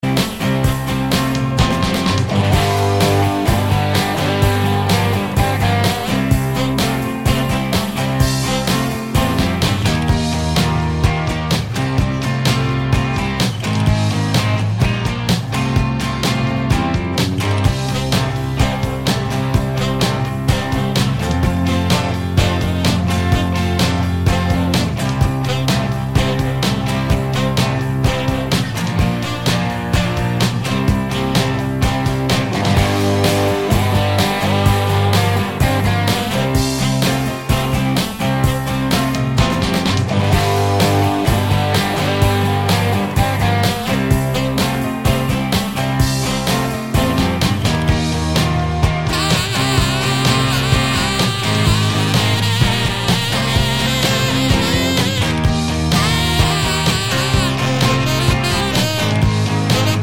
no Backing Vocals Rock 3:51 Buy £1.50